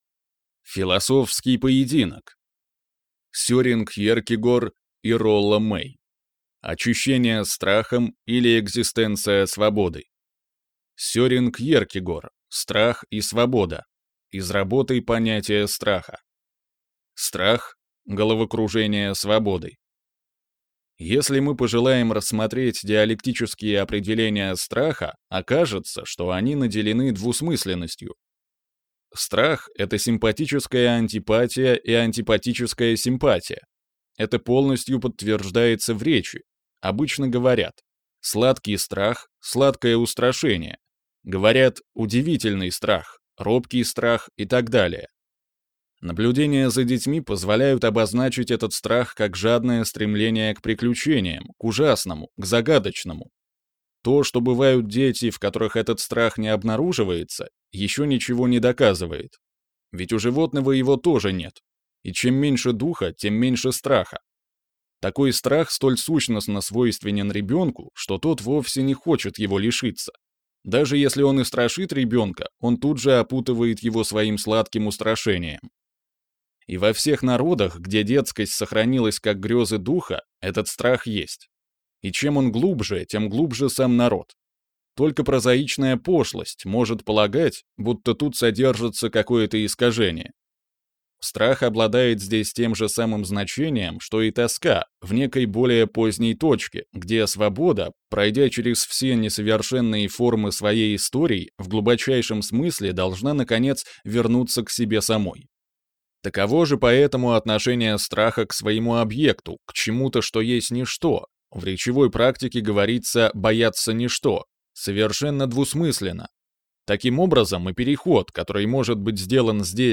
Аудиокнига Очищение страхом или Экзистенция свободы | Библиотека аудиокниг